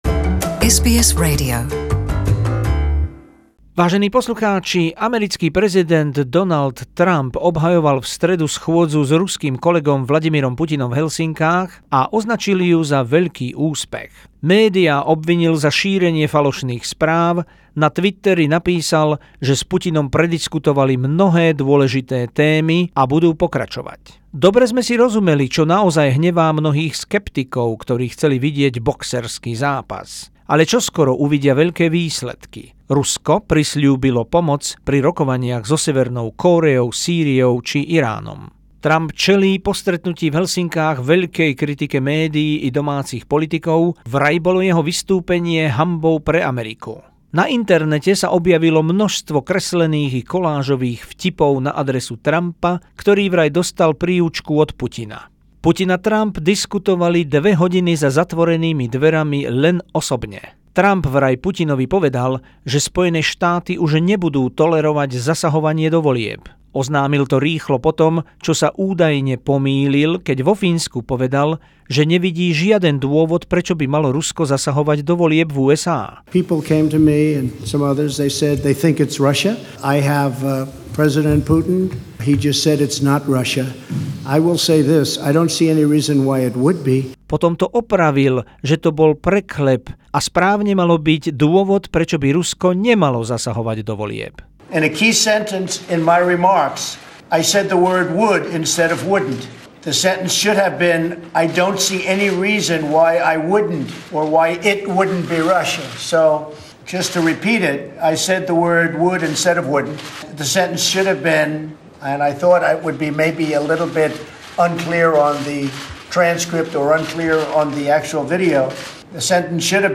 Reportáž z vrcholnej schôdze prezidentov USA a Ruska Donalda Trumpa a Vladimira Putina v Helsinkách zo spravodajskej dielne SBS.